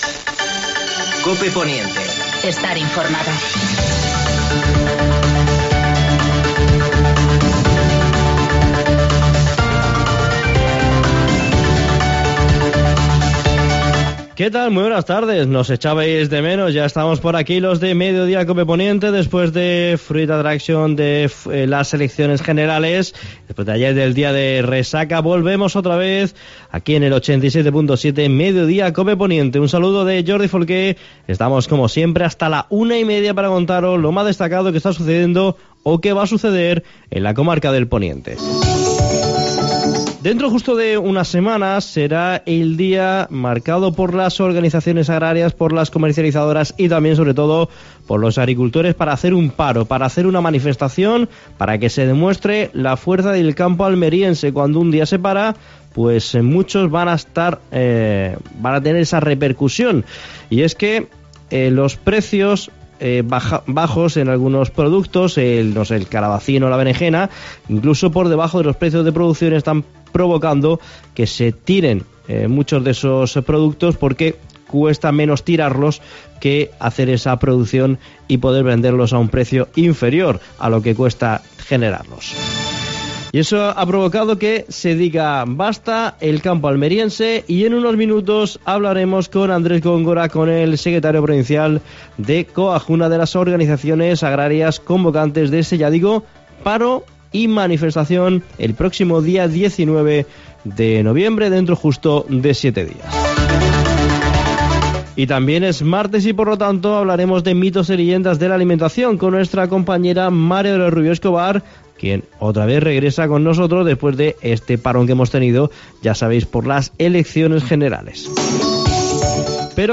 AUDIO: Actualidad en el Poniente. Entrevista